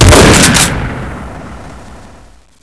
dbarrel1.wav